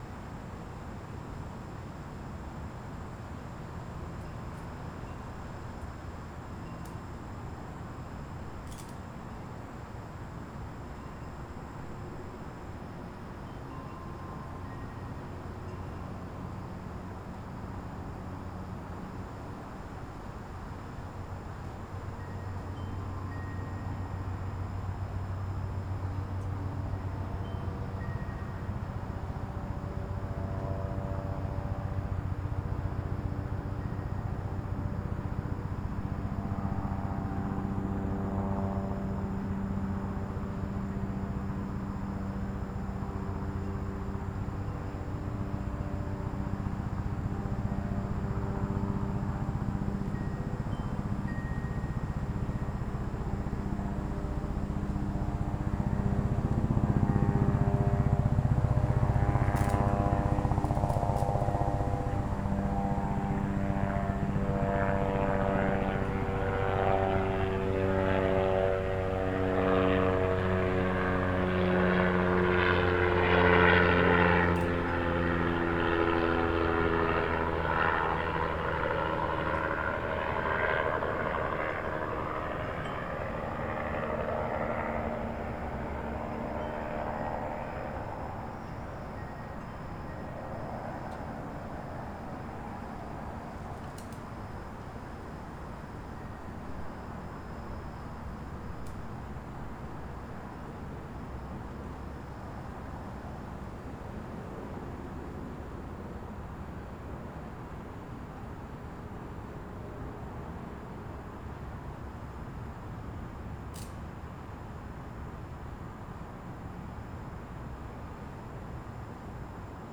Tetrahedral Ambisonic Microphones
Sample #7: Helicopter (02:11) (11.5MB/file).
B-format files for experimental mic.
Helicopter passing over my front yard on April 29, 2008.